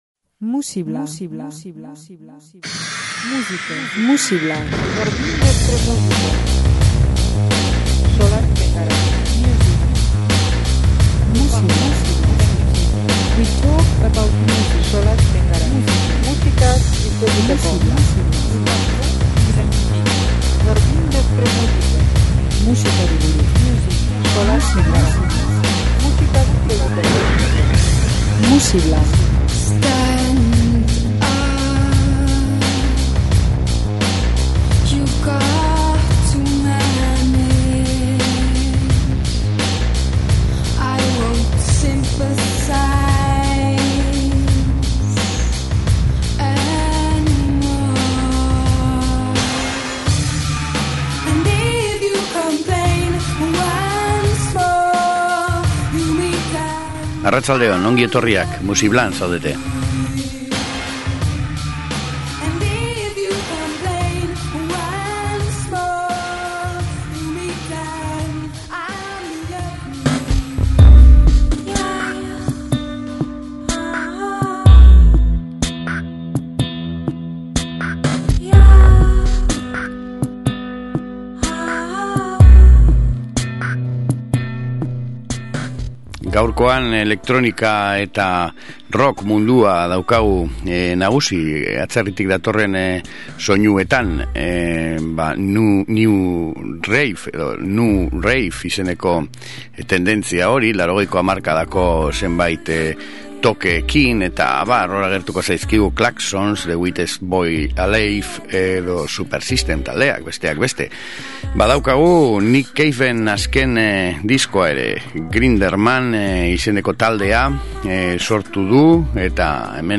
nu rave